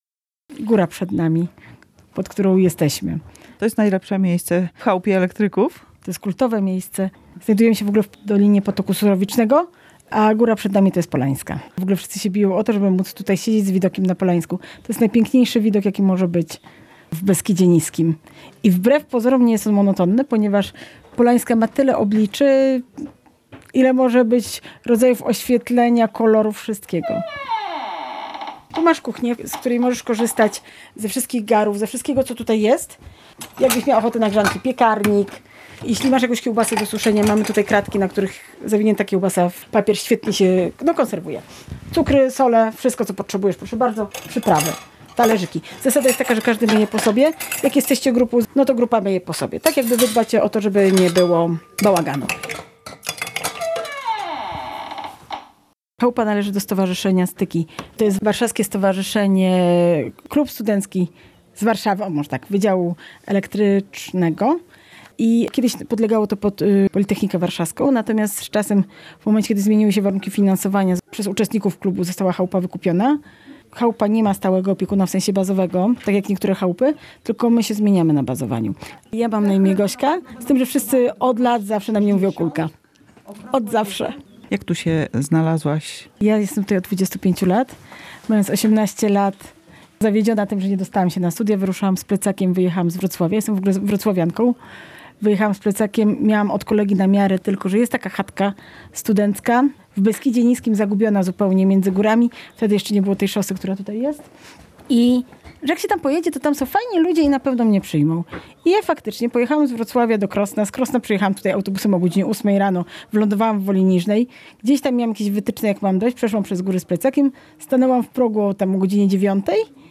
opowiadają o niej stali bywalcy i przypadkowi turyści